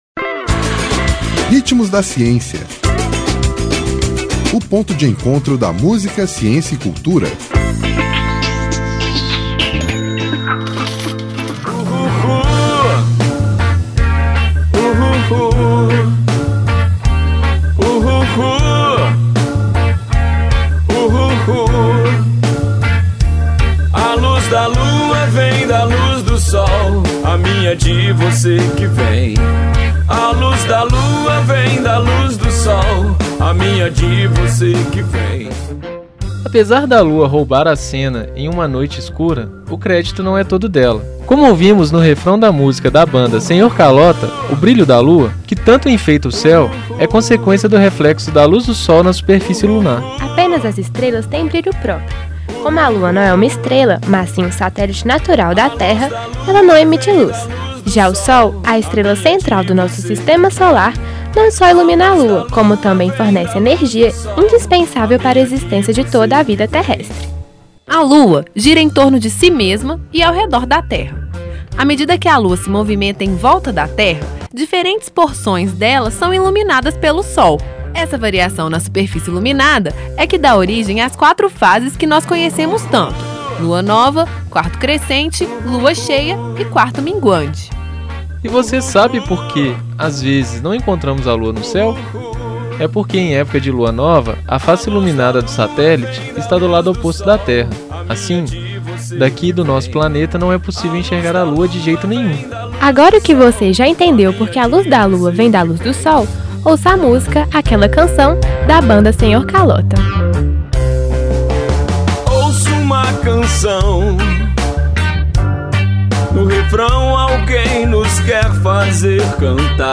Apesar da Lua roubar a cena em uma noite escura, o crédito não é todo dela!  Como ouvimos no refrão da música da banda Senhor Kalota, o brilho da Lua, que tanto enfeita o céu, é consequência do reflexo da luz do Sol na superfície lunar…Ouça todo o programa: